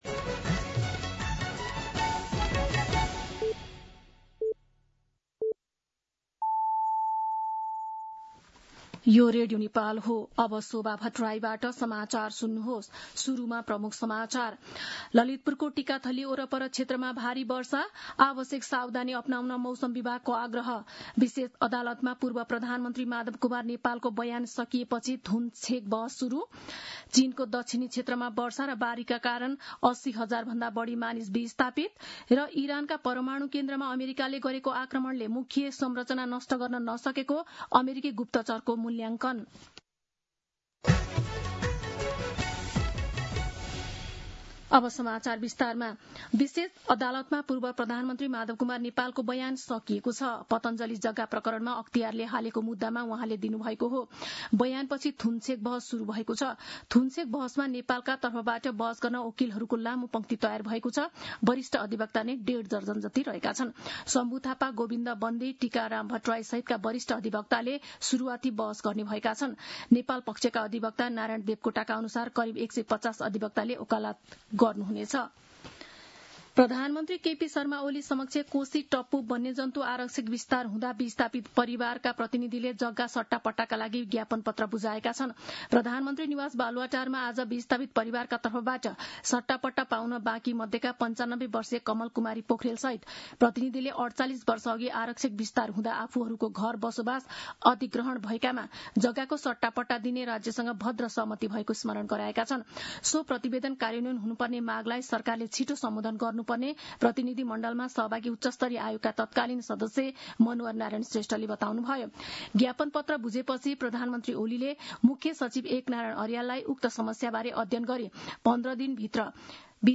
An online outlet of Nepal's national radio broadcaster
दिउँसो ३ बजेको नेपाली समाचार : ११ असार , २०८२
3-pm-News-11.mp3